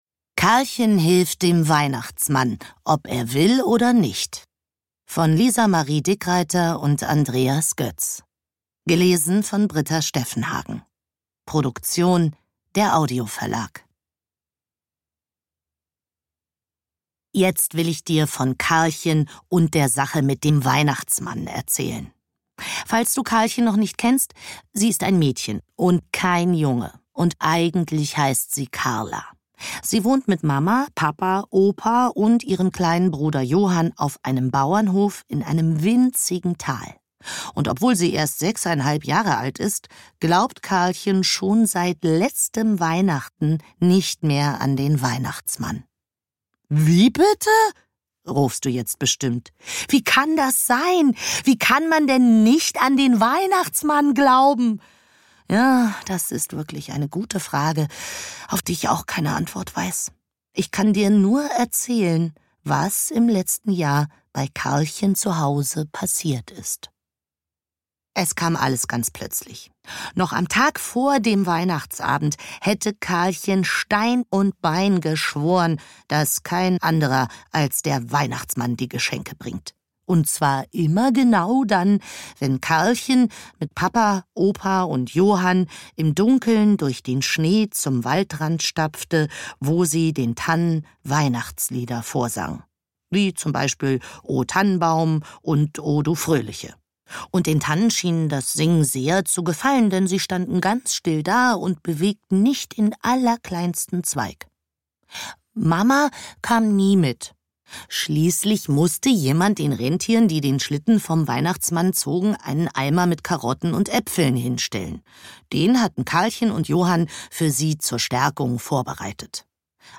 Hörbücher, Kinder- und Jugend-Hörbücher, Weihnachtsgeschichten- und lieder